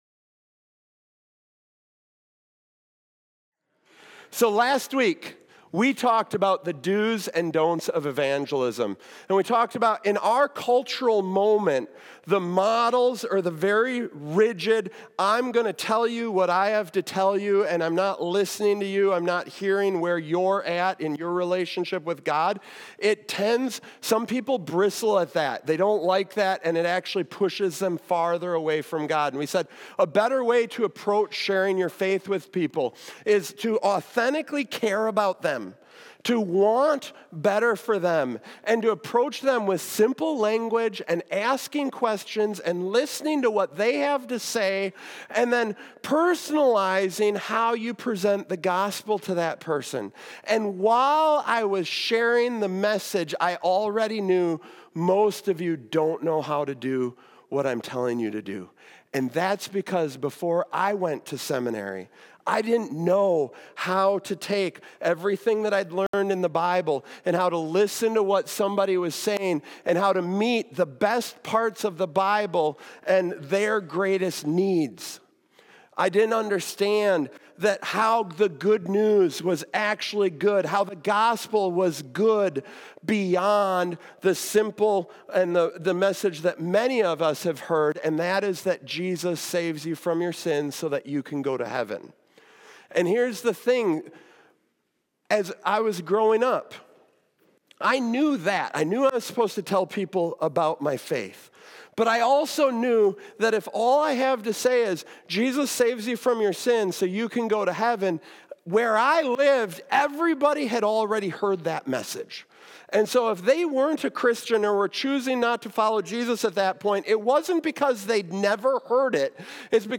Only-the-sermon.m4a